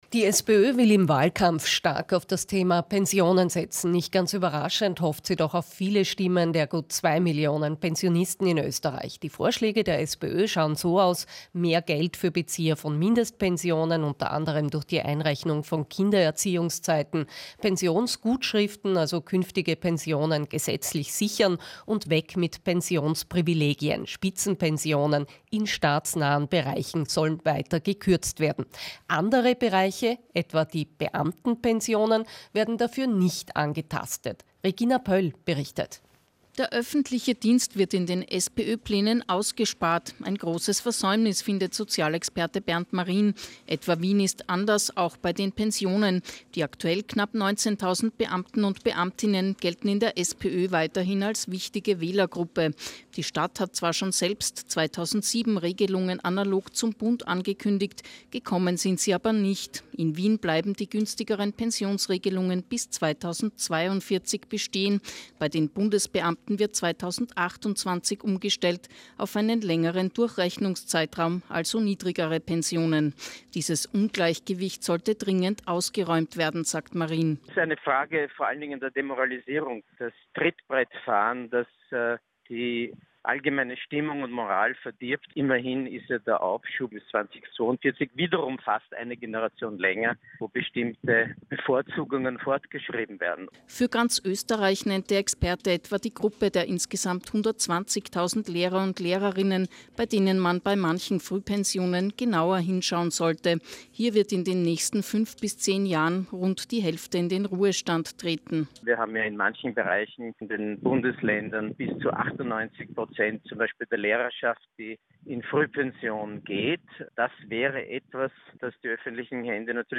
Mittagsjournal Interview